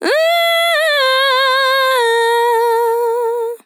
TEN VOCAL FILL 28 Sample
Categories: Vocals Tags: dry, english, female, fill, sample, TEN VOCAL FILL, Tension